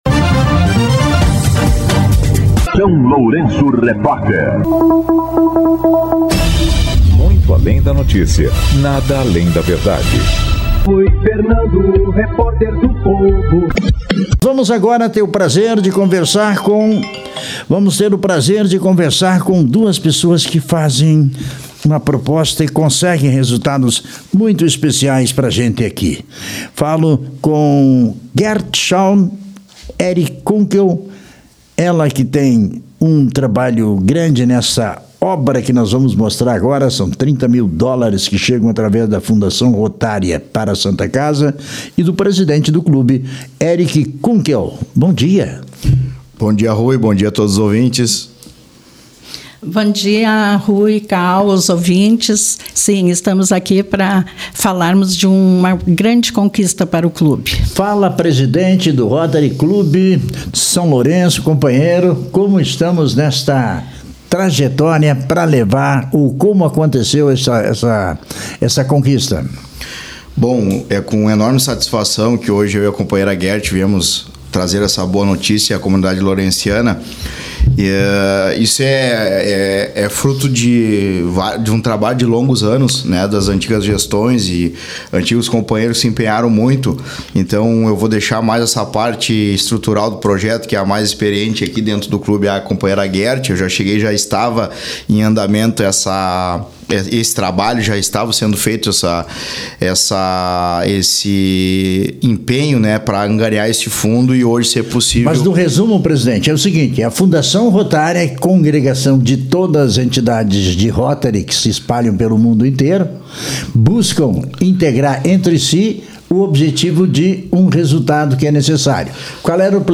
Entrevista com o Rotary Club São Lourenço do Sul